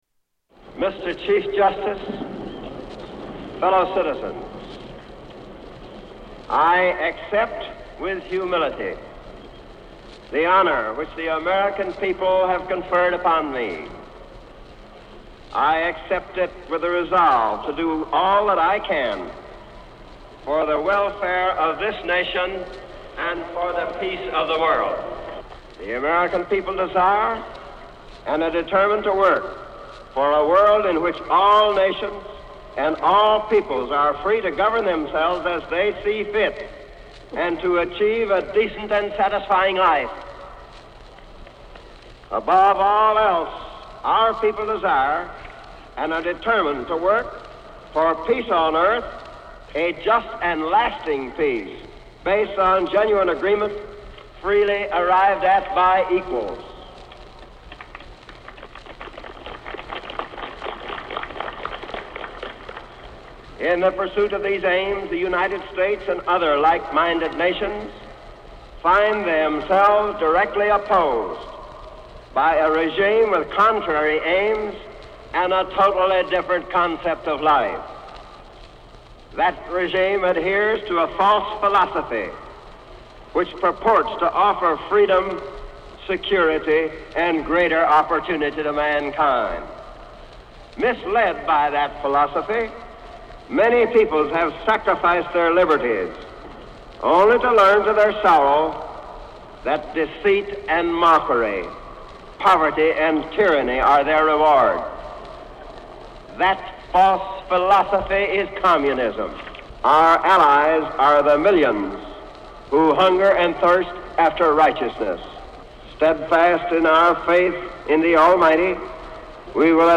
Tags: Famous Inaguration clips Inaguration Inaguration speech President Obama